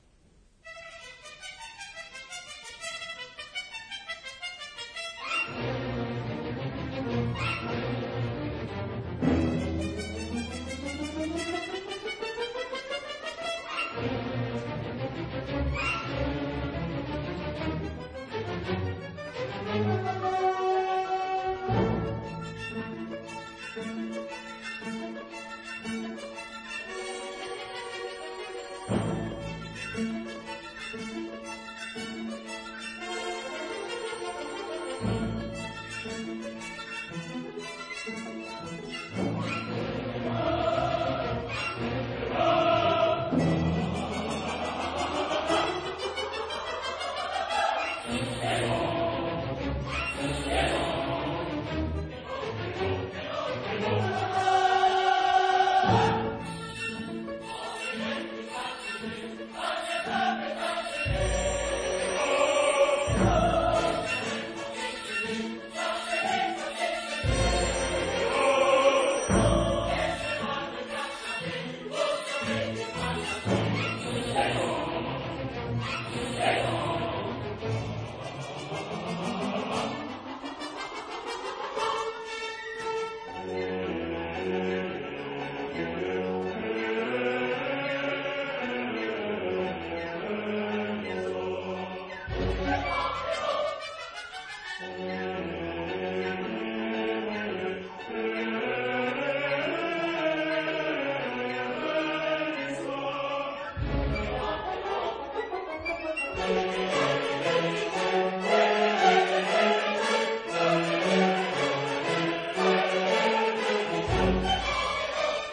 這兩套曲目都是很好的範例，層次相當清楚，動態幾乎不壓縮。
可以很清楚地區分不同樂器所在、與其質感。
活潑有力